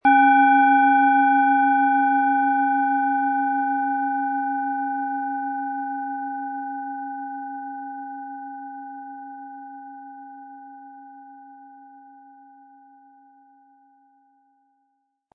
Planetenschale® Geistige Wachheit & Nachdenken und Verstehen können mit Merkur, Ø 12,4 cm, 180-260 Gramm inkl. Klöppel
Planetenton 1
Um den Original-Klang genau dieser Schale zu hören, lassen Sie bitte den hinterlegten Sound abspielen.
SchalenformBihar
MaterialBronze